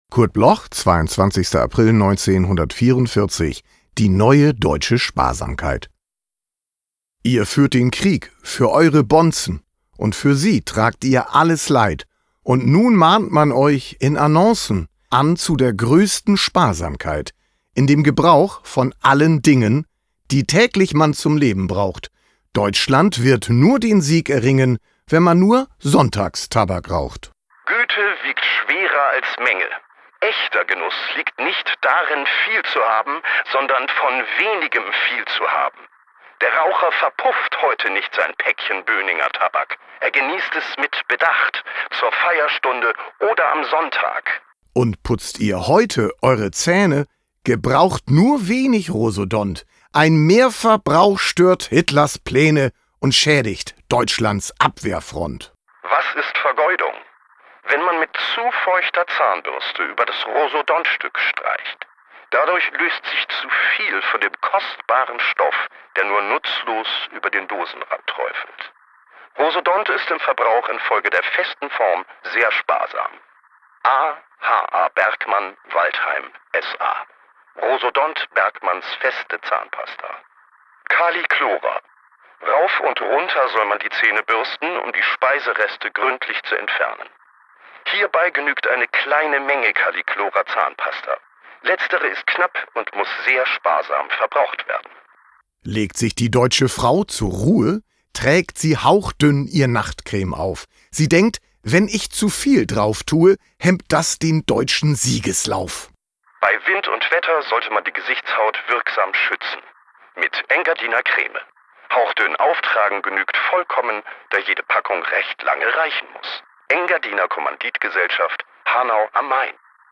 Recording: The Soundshack, Hamburg · Editing: Kristen & Schmidt, Wiesbaden